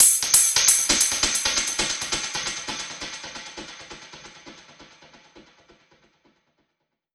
Index of /musicradar/dub-percussion-samples/134bpm
DPFX_PercHit_E_134-02.wav